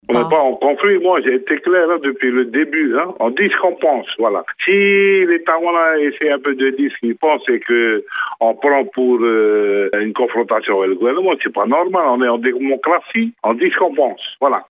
Mais mardi matin, le président du SPC, Cyril Tetuanui, a assuré à Radio 1 qu’il assumait totalement cette résolution du congrès des communes.